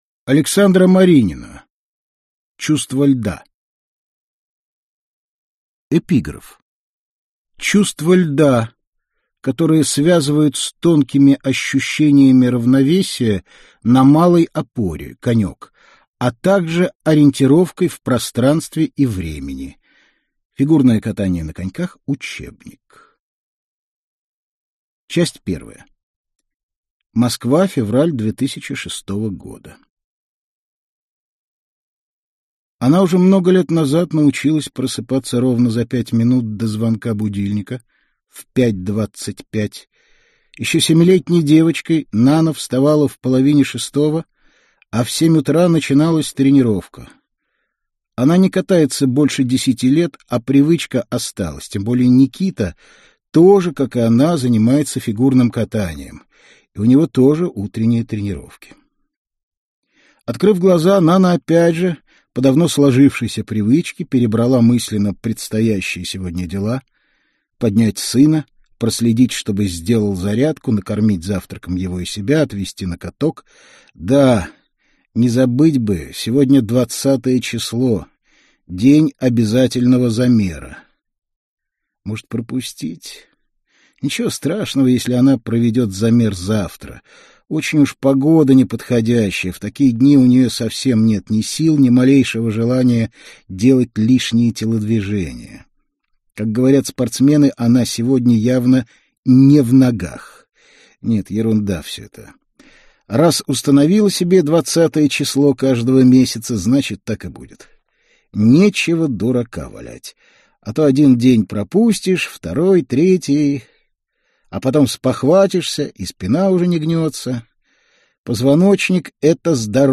Аудиокнига Чувство льда. Часть 1 | Библиотека аудиокниг